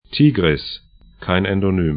Tigris 'ti:grɪs Nahr Dijlah 'naxər 'di:ʤla: ar Fluss / stream 29°56'N, 48°35'E